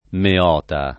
[ me 0 ta ]